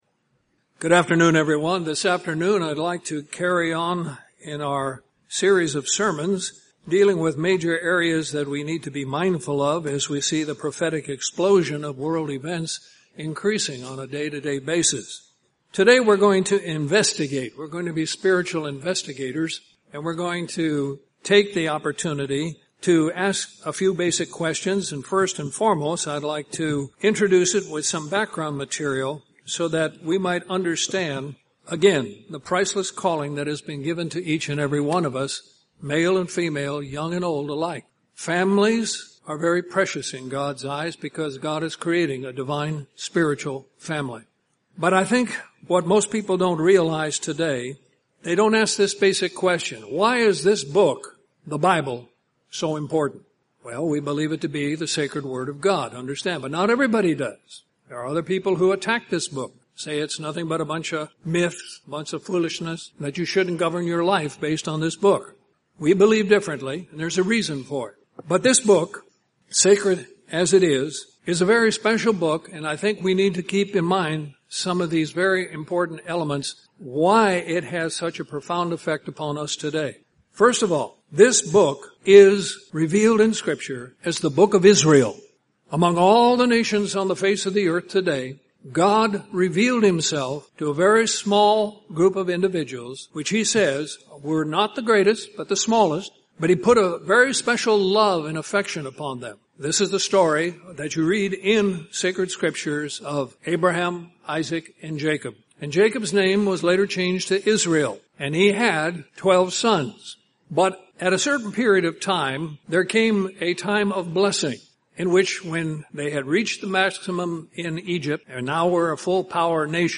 Given in Columbus, GA Central Georgia
UCG Sermon Studying the bible?